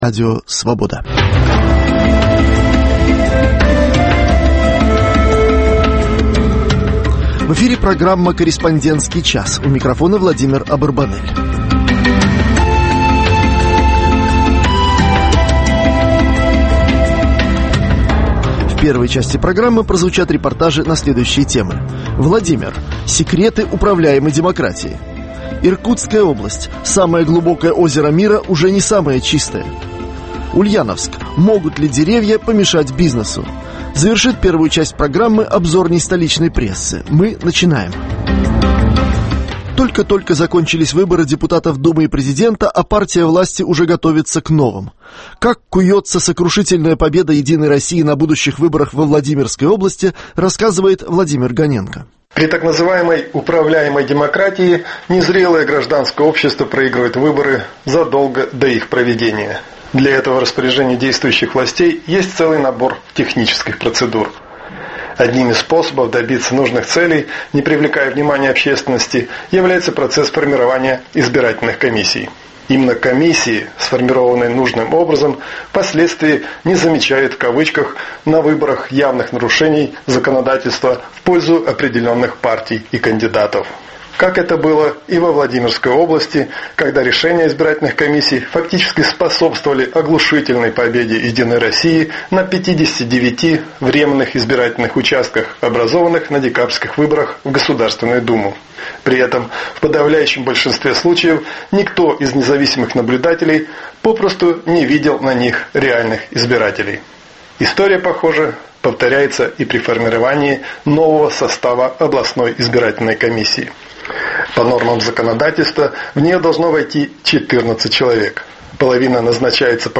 Еженедельная серия радиоочерков о жизни российской провинции. Авторы из всех областей России рассказывают о проблемах повседневной жизни обычных людей.